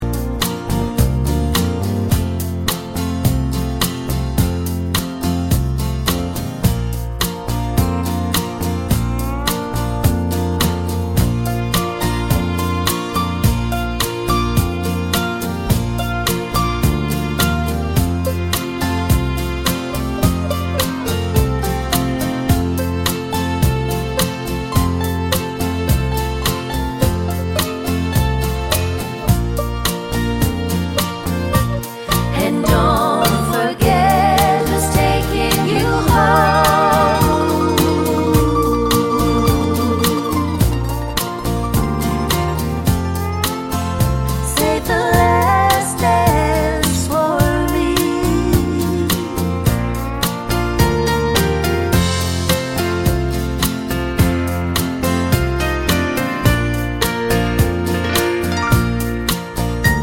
no Backing Vocals Country (Female) 3:37 Buy £1.50